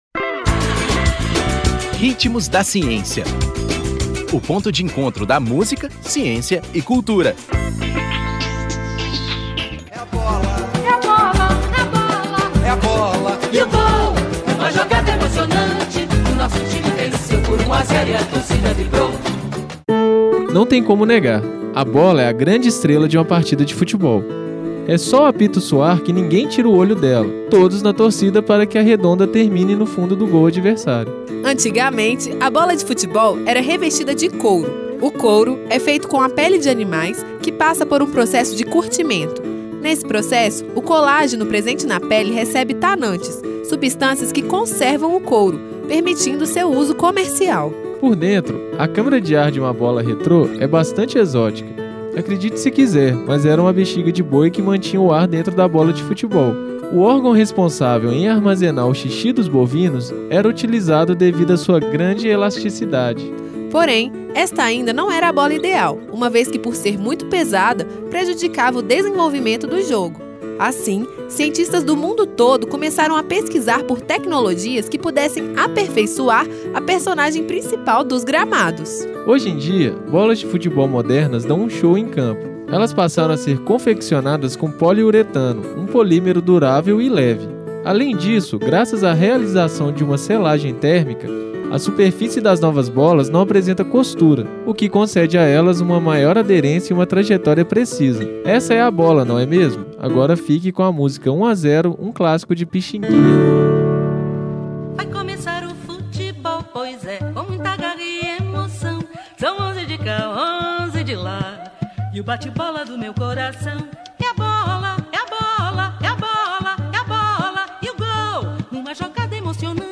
Intérprete: Arranco de Varsóvia